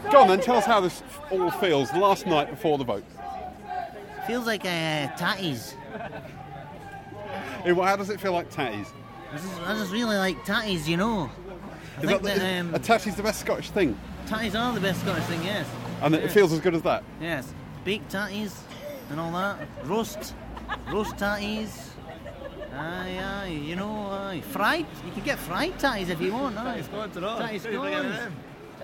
I interviewed a Yes supporter on a rally in Edinburgh. Here's how he described his feeling about tomorrow's vote.